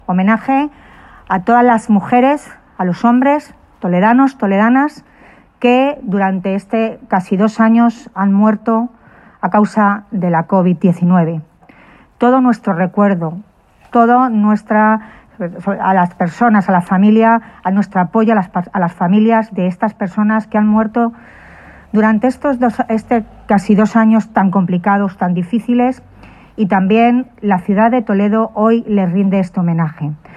La alcaldesa de Toledo, Milagros Tolón, ha tomado la palabra en este Día de la Ciudad y San Ildefonso en el acto de inauguración de la escultura-homenaje a las víctimas de la covid-19 con el recuerdo puesto en aquellos toledanos y toledanas que han fallecido a consecuencia de la pandemia.
Mialgros Tolón, alcaldesa de Toledo